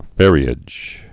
(fĕrē-ĭj)